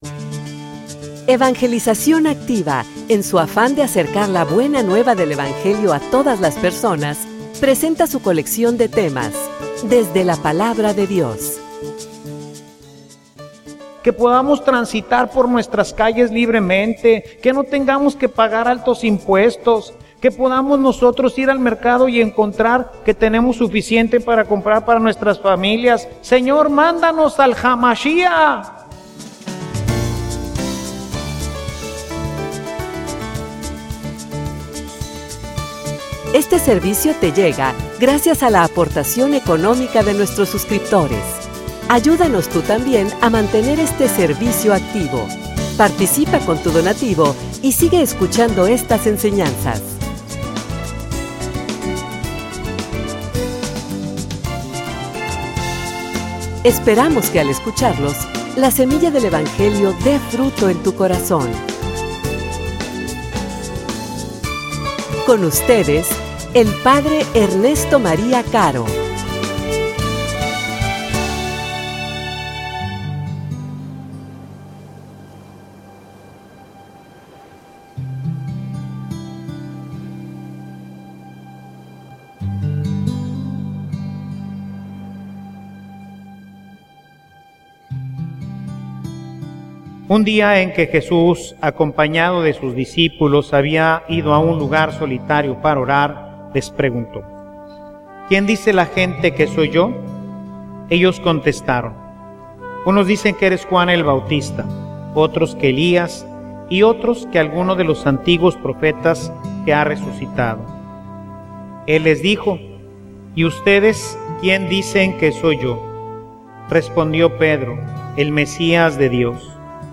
homilia_Un_mesianismo_diferente.mp3